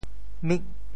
“宓”字用潮州话怎么说？